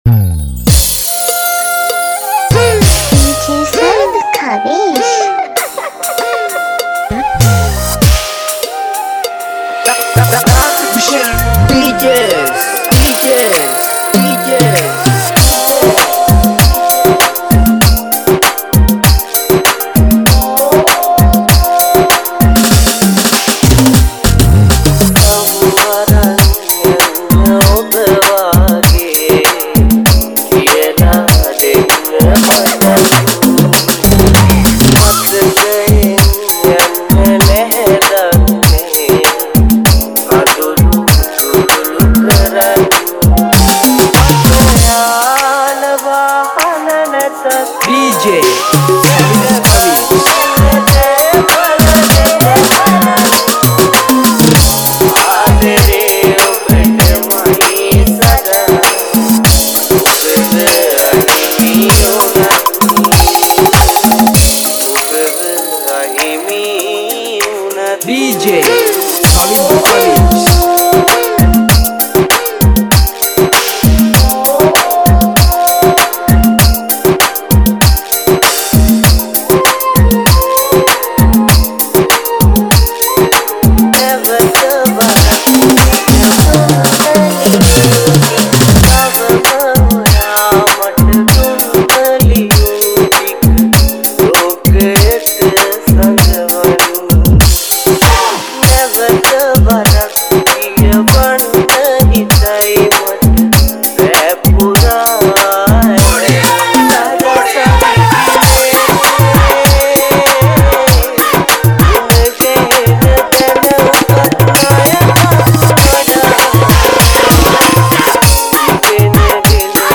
DJ & Remixes